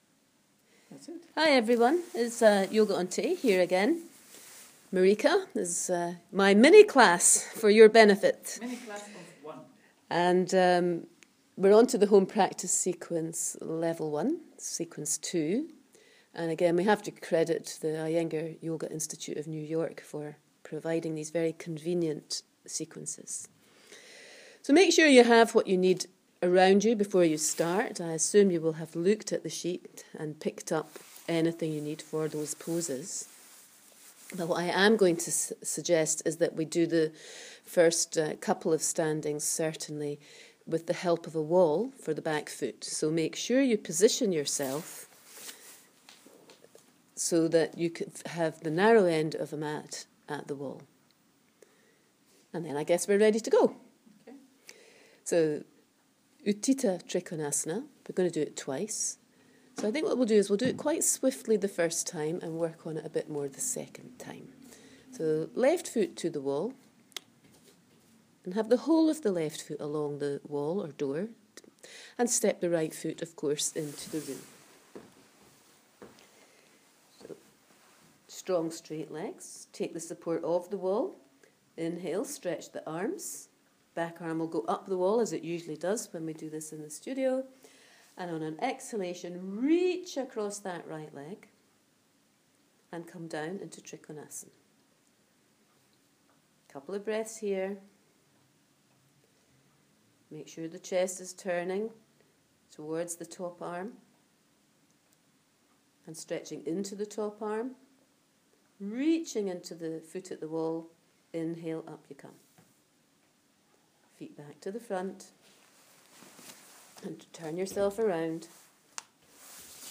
Members' Home Practice